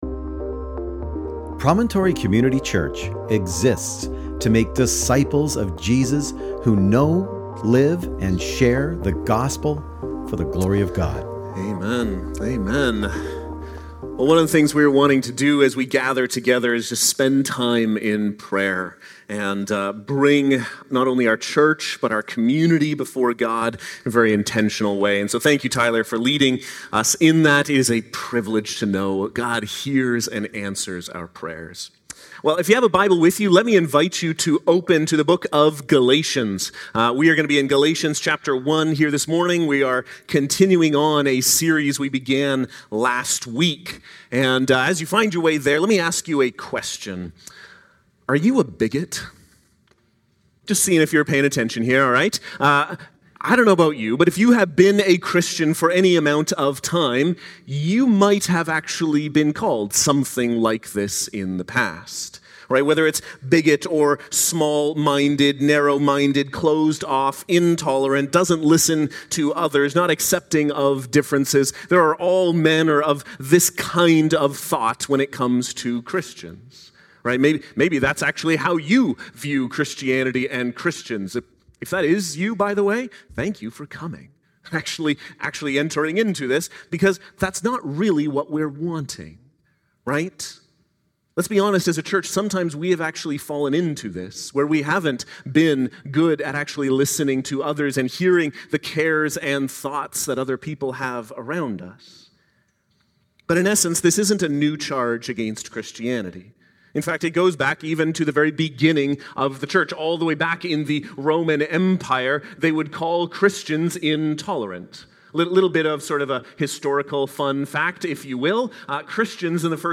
Sermon Text: Galatians 1:6-10